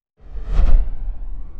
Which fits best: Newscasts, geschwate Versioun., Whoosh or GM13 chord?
Whoosh